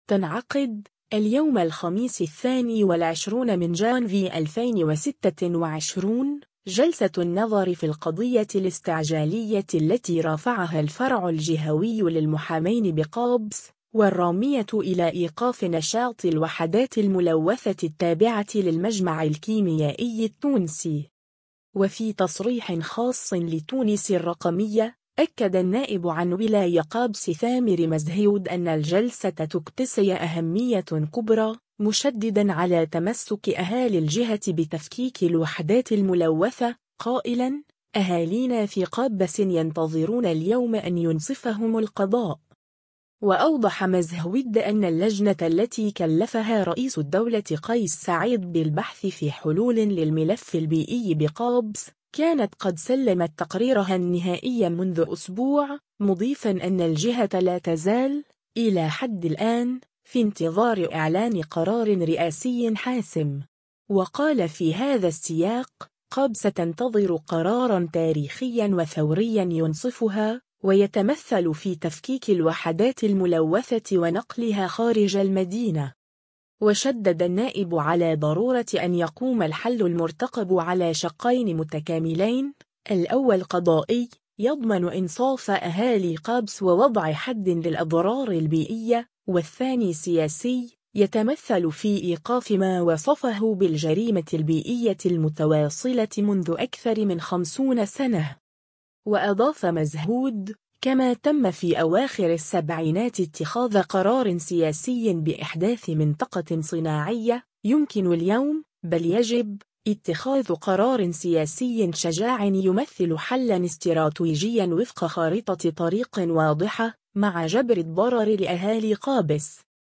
وفي تصريح خاص لـ”تونس الرقمية”، أكد النائب عن ولاية قابس ثامر مزهود أن الجلسة تكتسي أهمية كبرى، مشددًا على تمسّك أهالي الجهة بتفكيك الوحدات الملوّثة، قائلاً: “أهالينا في قابس ينتظرون اليوم أن ينصفهم القضاء”.